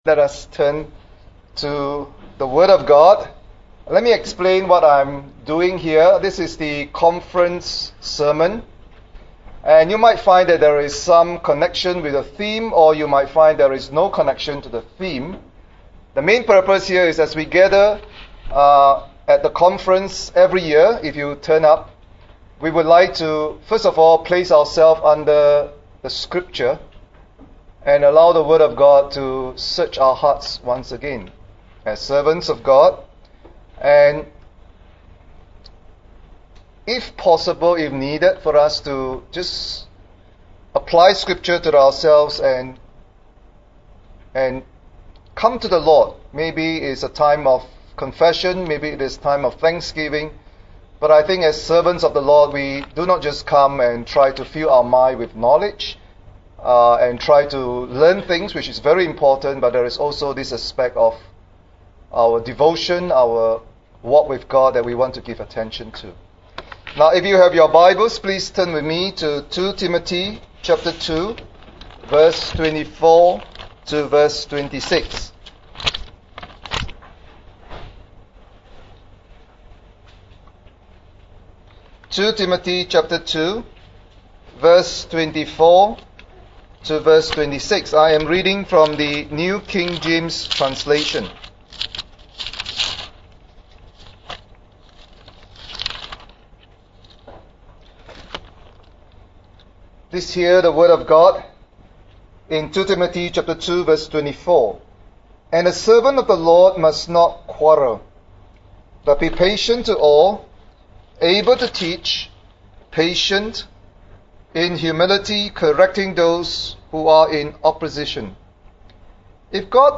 Church Leader’s Conference 2015 – Shalom Church (Reformed Baptist) Singapore